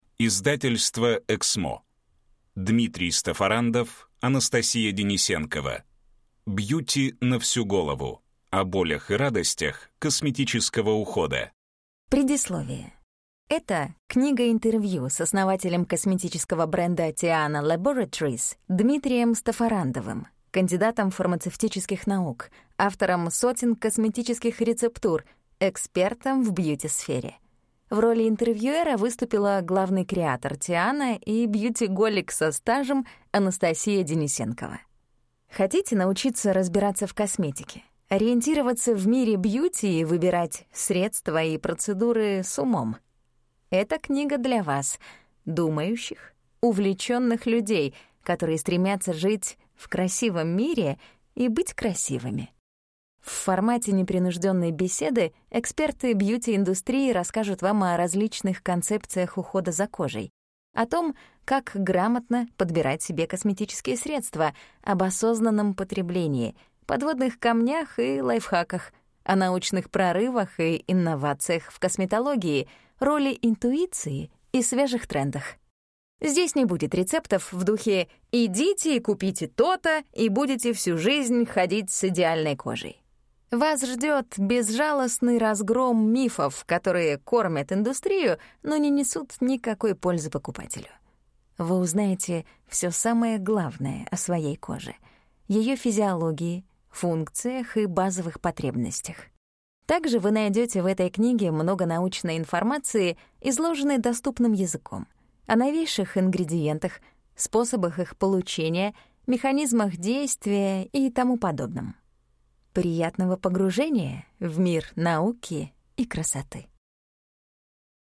Аудиокнига Бьюти на всю голову. Все, что нужно знать о современном уходе, инновациях в косметике и уловках индустрии красоты | Библиотека аудиокниг